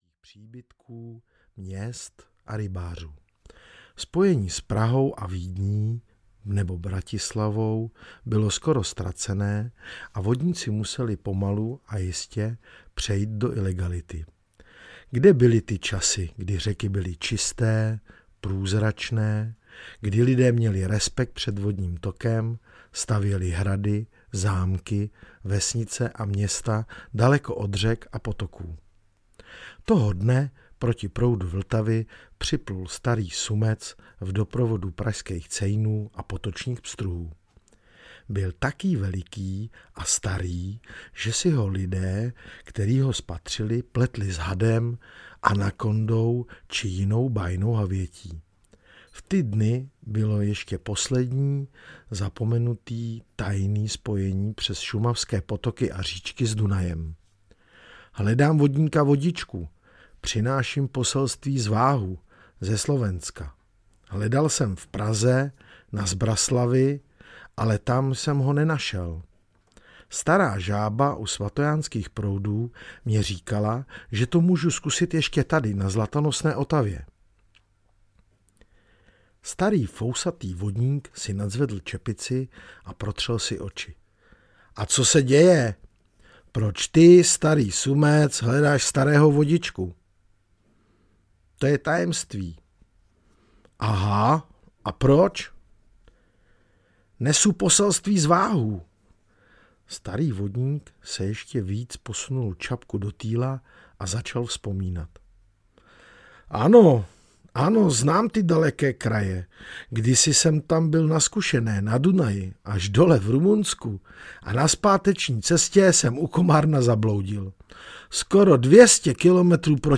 Legenda o vodníkovi Vodičkovi audiokniha
Ukázka z knihy